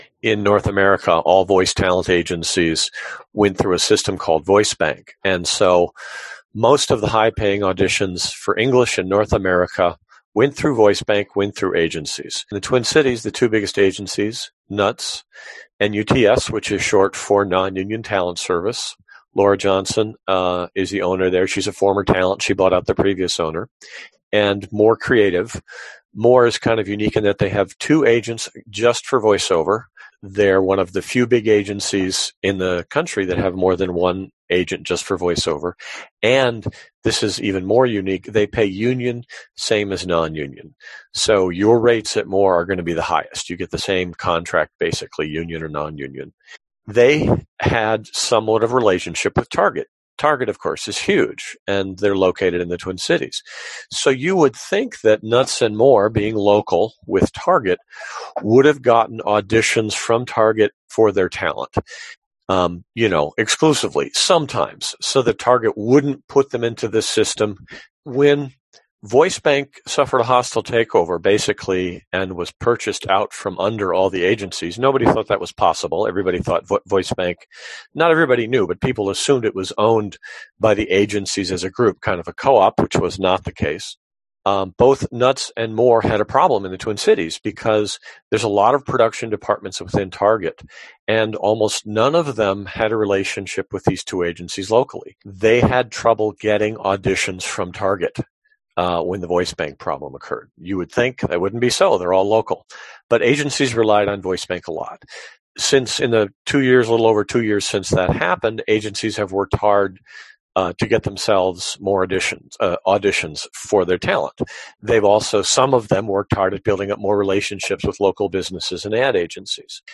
Attend each live workshop if you can to be able to ask questions and hear the latest information.